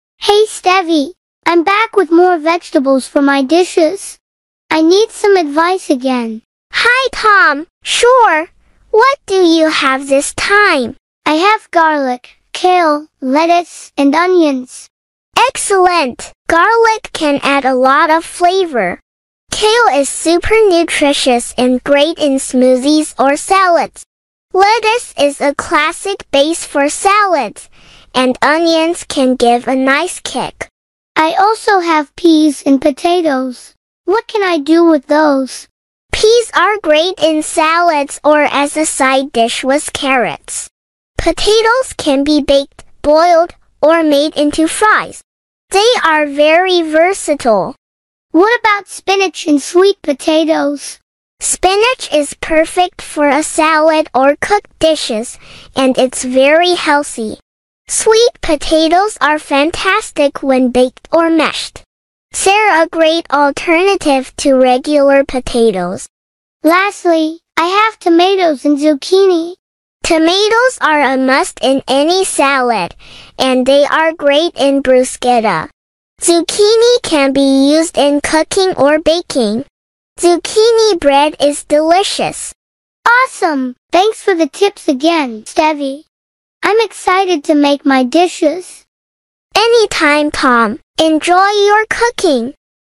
Dialogue 2